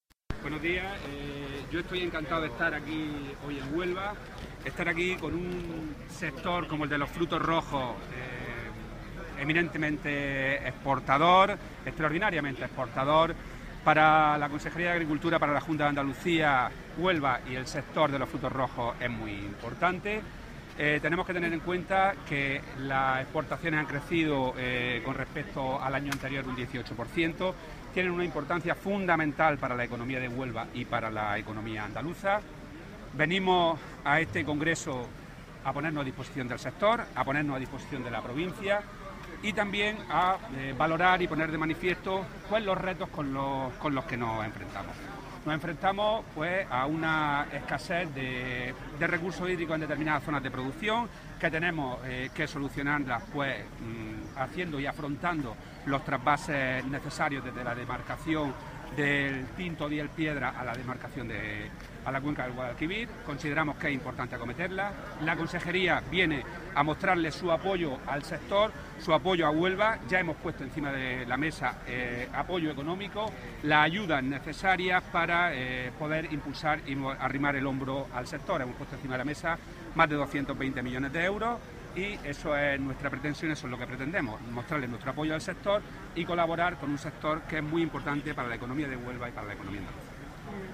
Rodrigo Sánchez Haro ha participado en el III Congreso Internacional de Frutos Rojos que organiza Freshuelva
Declaraciones de Rodrigo Sánchez sobre el sector de los frutos rojos en Andalucía